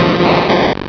sovereignx/sound/direct_sound_samples/cries/mr_mime.aif at master